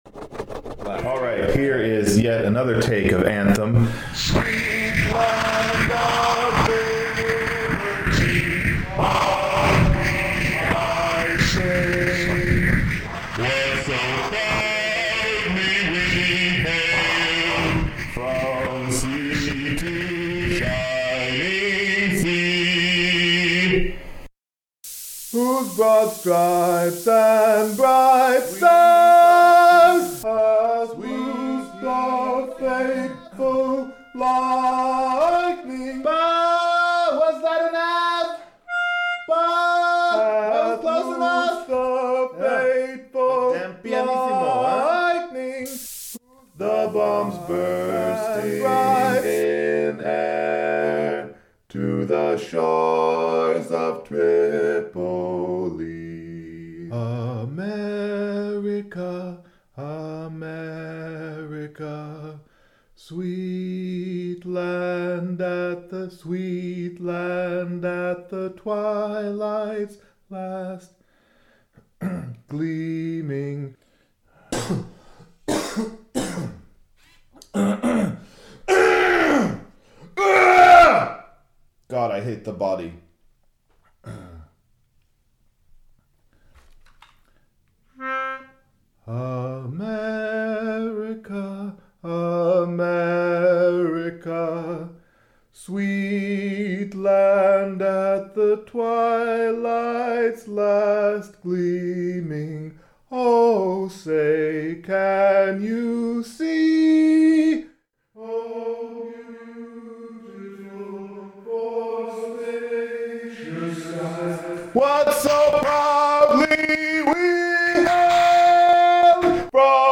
bass-baritone or tenor solo in my dining room